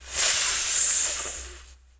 snake_hiss_09.wav